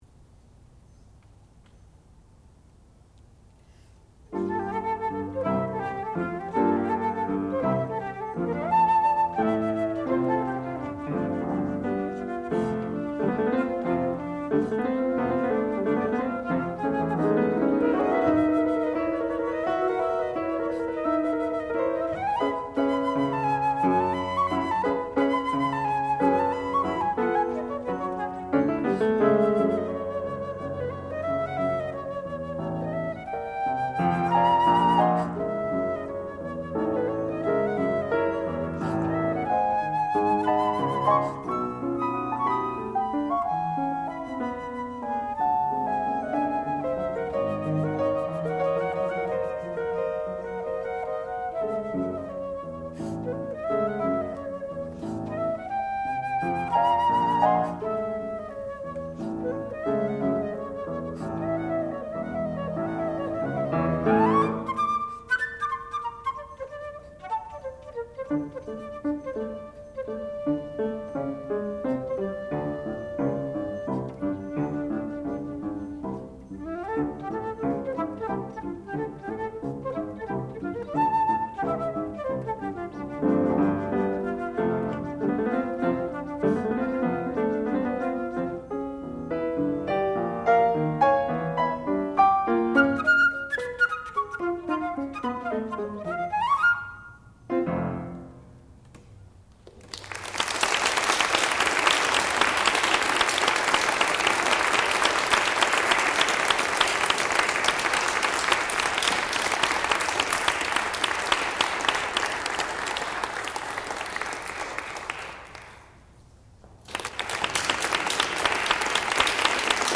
classical music
piano
flute
Advanced Recital